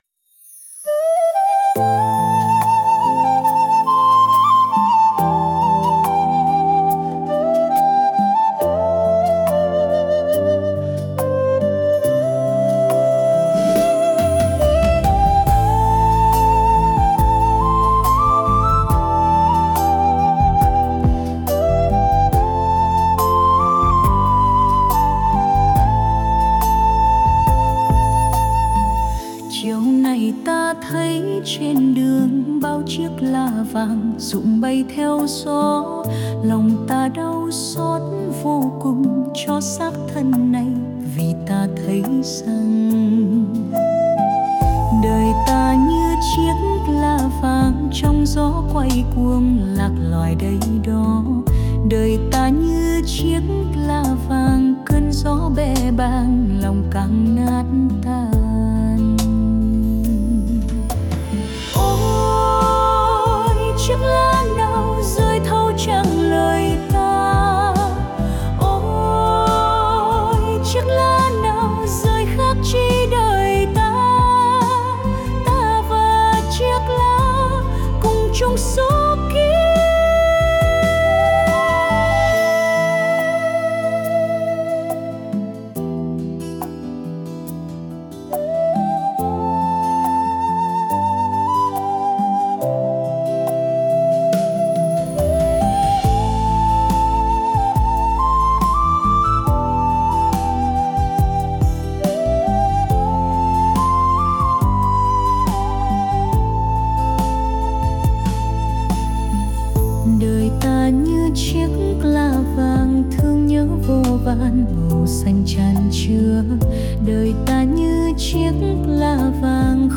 NHẠC THƠ- ĐỜI TA NHƯ CHIẾC LÁ VÀNG-Ý ĐẠO ĐỨC THẦY LƯƠNG SĨ HẰNG-THƠ HOÀNG THI THƠ